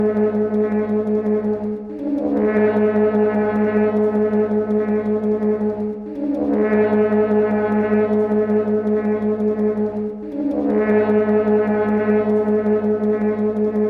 描述：一个人在街上吹萨克斯风
标签： 伦敦 城市 音乐 萨克斯 萨克斯管 街道
声道立体声